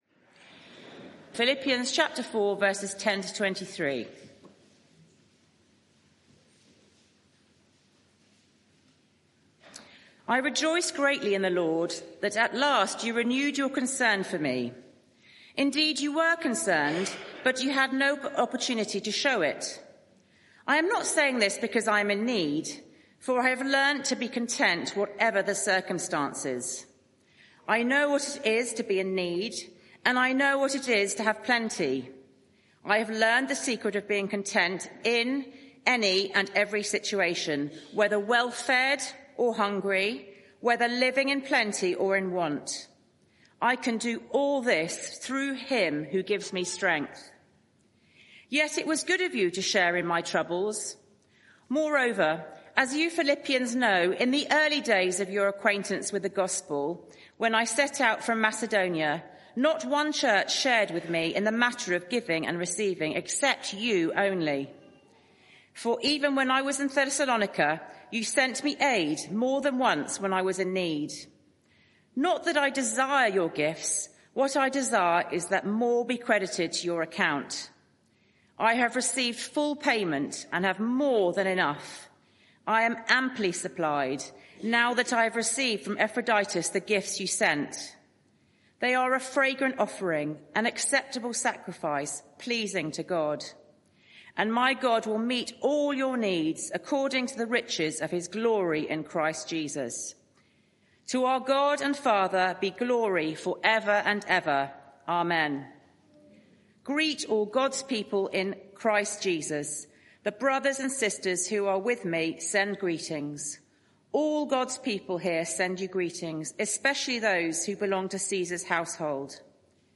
Media for 6:30pm Service on Sun 09th Mar 2025 18:30 Speaker
Sermon Search the media library There are recordings here going back several years.